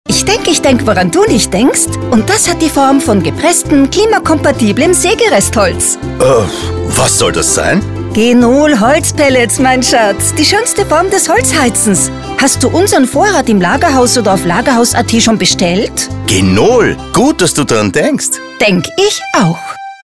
Hörfunk